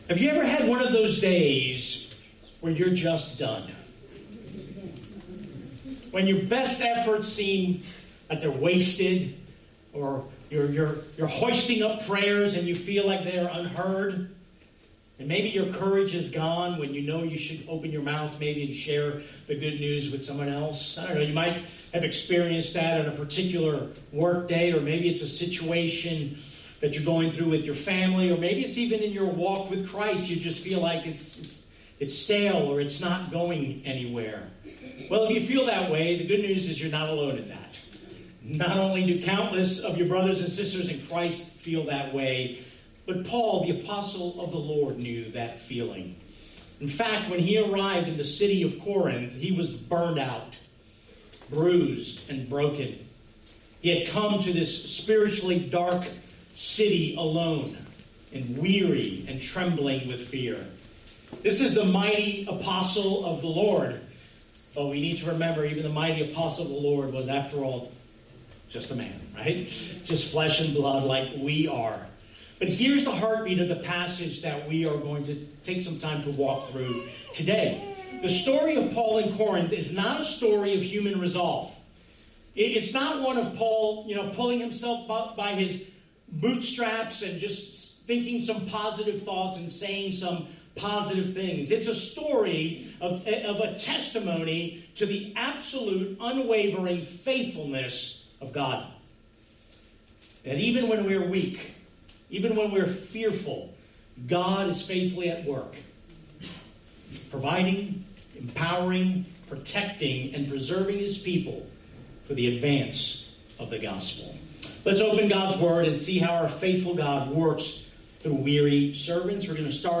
Sent Church Lake Mary, Longwood, Sanford FL | Sermons